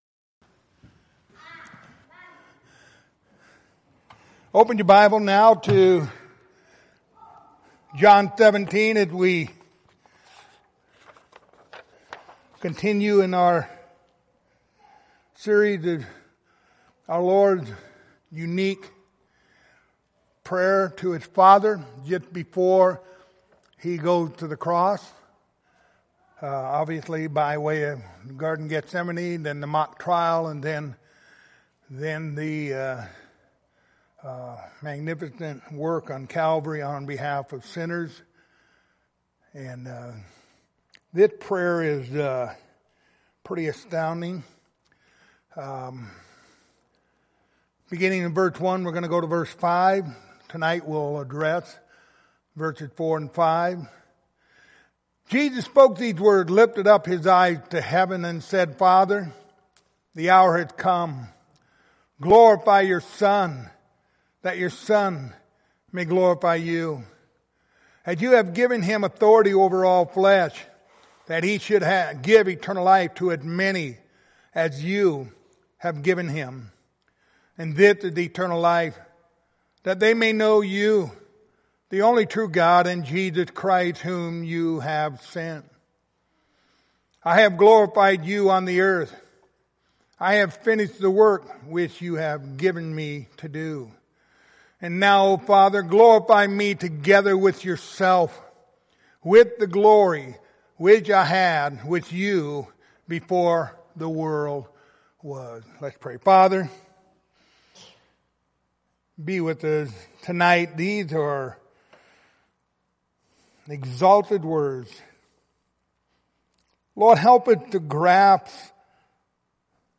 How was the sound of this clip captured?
Passage: John 17:4-5 Service Type: Wednesday Evening